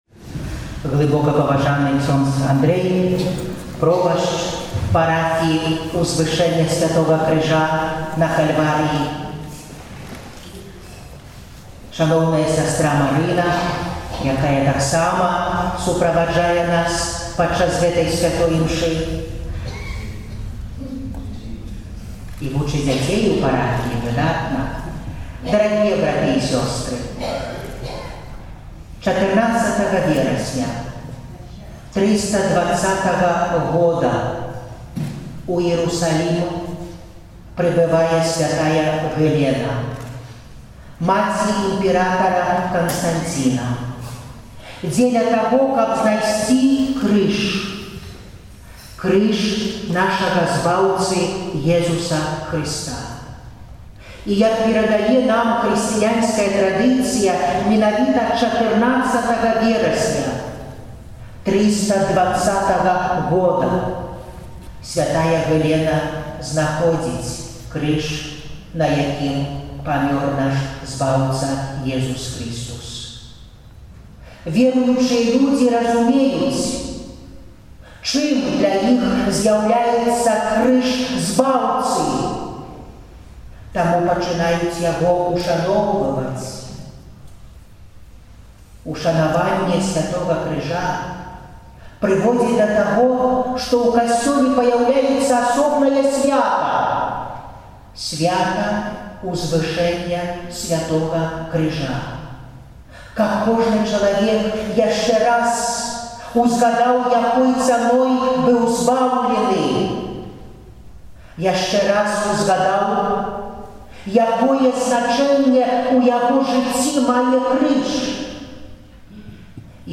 14 верасня, у свята Узвышэння Святога Крыжа, у парафіі Узвышэння Святога Крыжа на Кальварыі ў Мінску адбылася адпустовая ўрачыстасць. Святую Імшу цэлебраваў дапаможны біскуп Мінска-Магілёўскай архідыяцэзіі Аляксандр Яшэўскі SDB, які наведаў гэтую парафію ўпершыню.